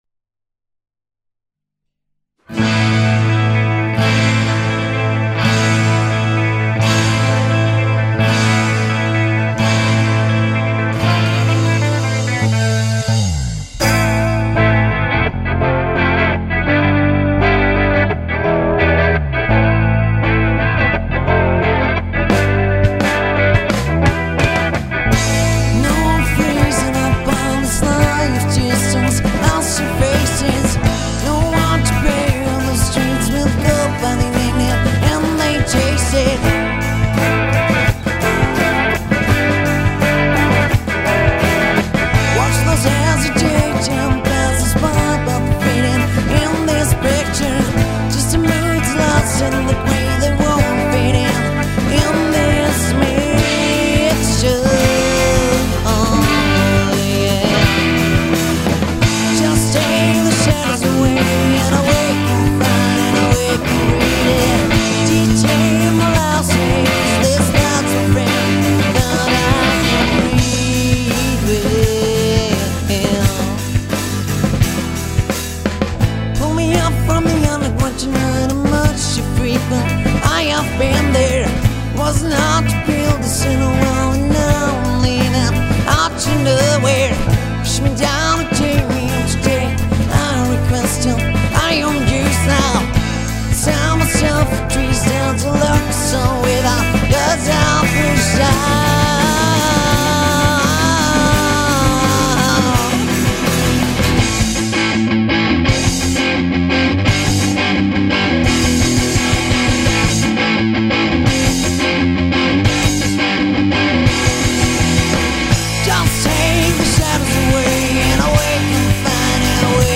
Rhythmusgitarre, Gesang
Leadgitarre, Back-Vocals
Bass
Schlagzeug
Demo Songs